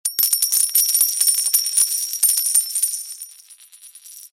eff_gold_rain.mp3